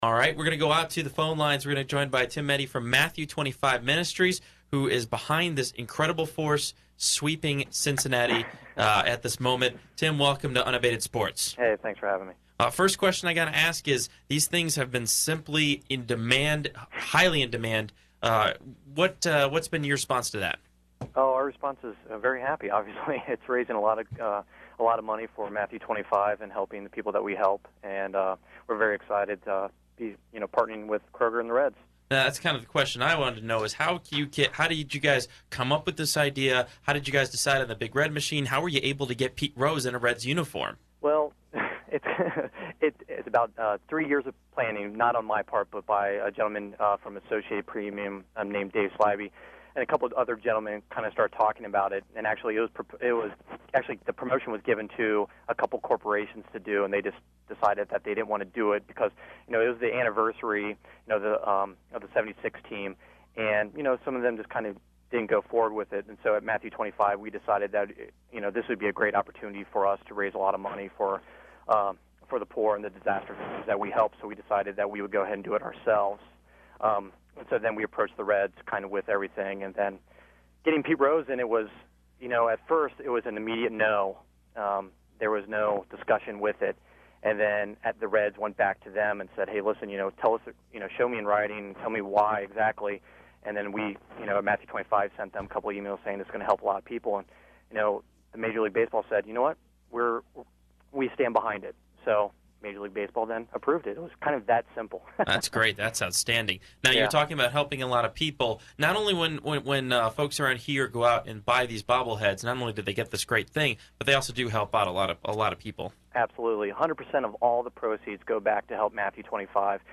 Recent Radio Interviews